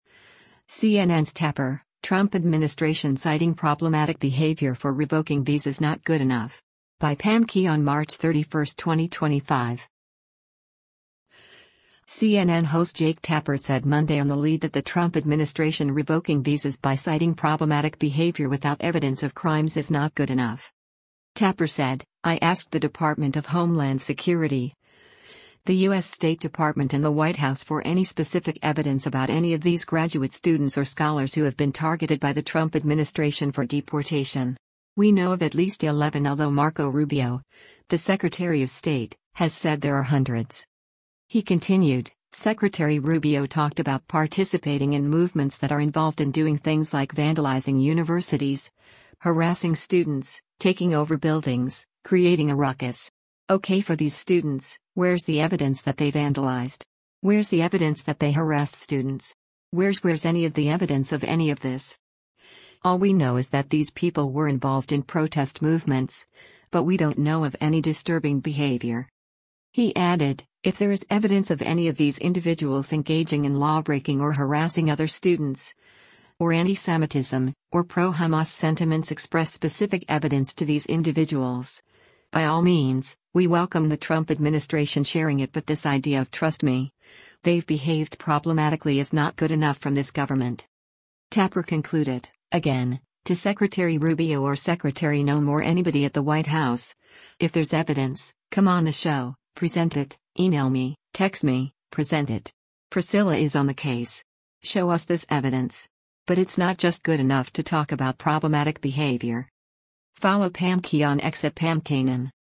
CNN host Jake Tapper said Monday on “The Lead” that the Trump administration revoking visas by citing problematic behavior without evidence of crimes is “not good enough.”